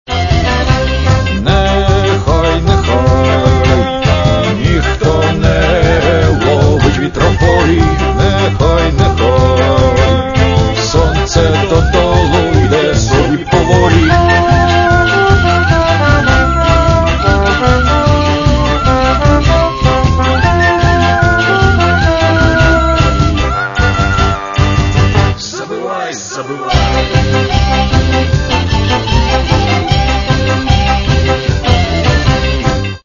Каталог -> Рок и альтернатива -> Фольк рок
соединяя энергию украинских, цыганских, ямайских мелодий.